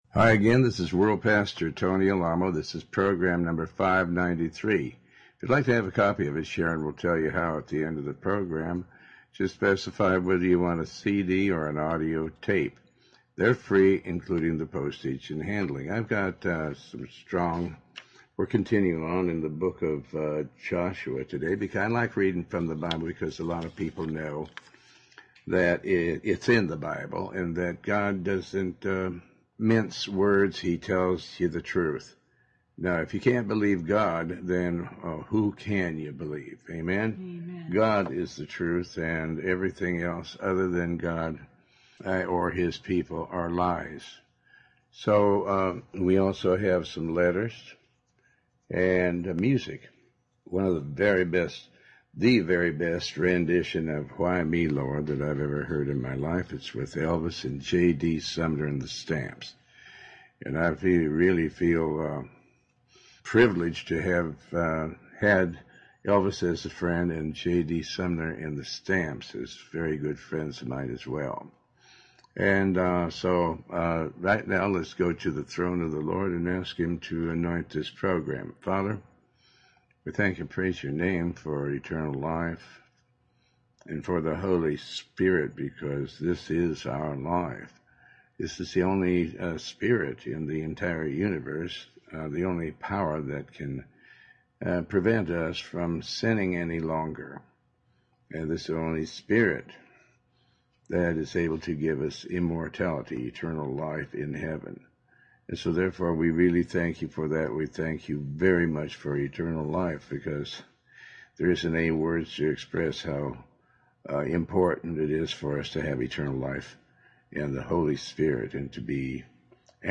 Talk Show
Show Host Pastor Tony Alamo